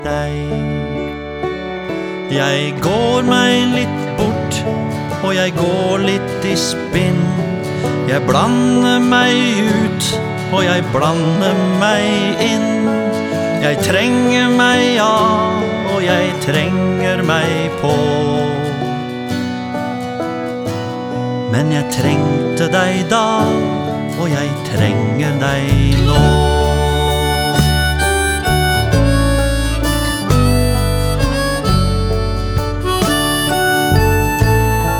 # Children's Music